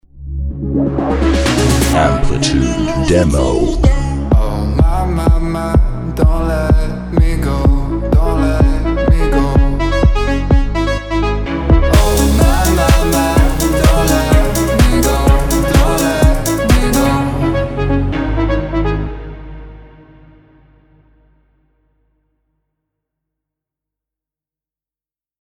126 BPM  C# Minor  12A
Deep House